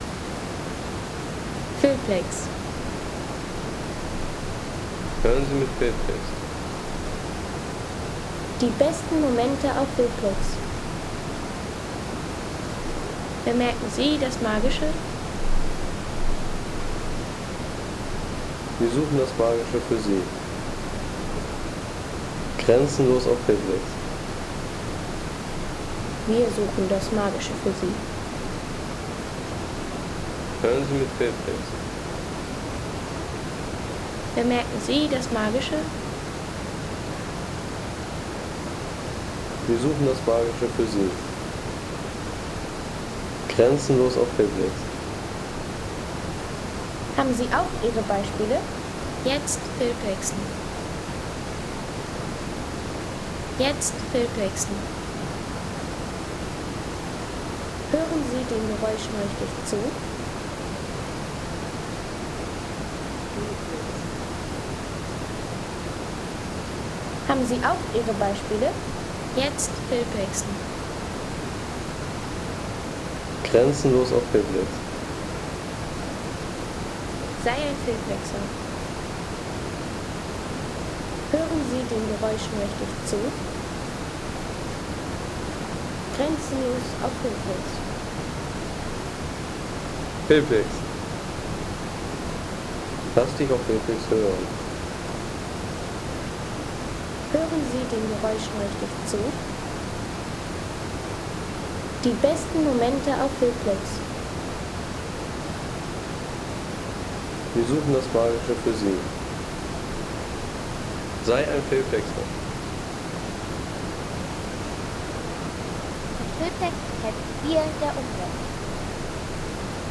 Plitvicer Seen Wasserfall Sound - Slapovi | Feelplex
Ein Wasserfall-Sound aus dem stufigen Herzen der Plitvicer Seen
Atmosphärischer Wasserfall-Sound von Slapovi im Nationalpark Plitvicer Seen.
Natürliche Slapovi-Atmosphäre aus den Plitvicer Seen mit klarem Wasser, Kaskaden und eindrucksvoller Landschaft für Film und Hintergrundszenen.